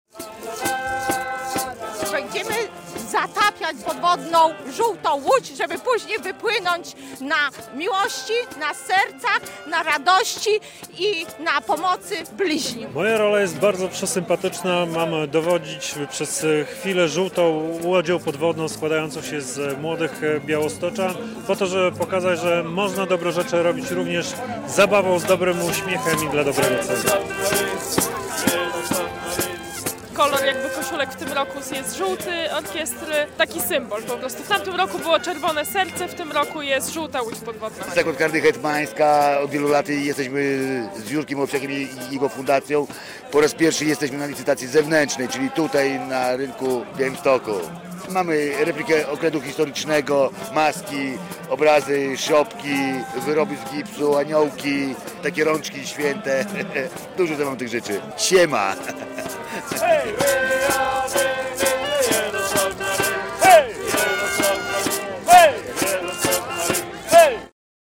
Żółta łódź podwodna podczas białostockiego finału WOŚP - relacja